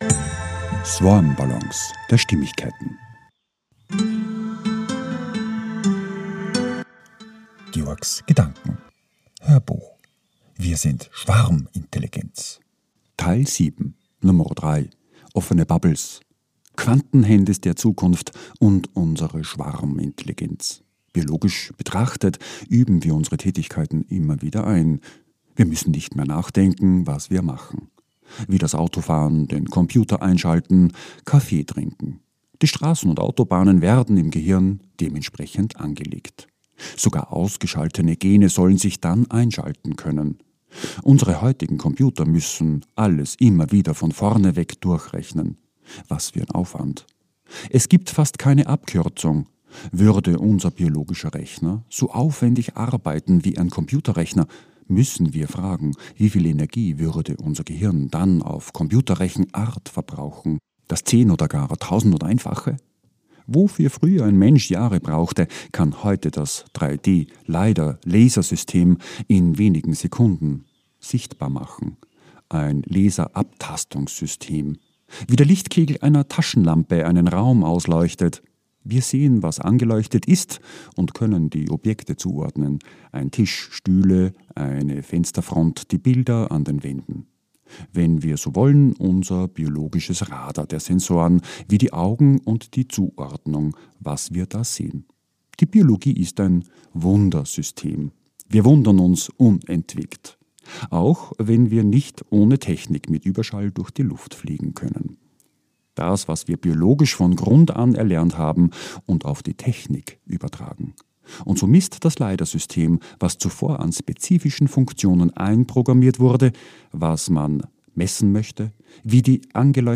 HÖRBUCH - 007/3 - WIR SIND SCHWARMINTELLIGENZ - Offene BUBBLES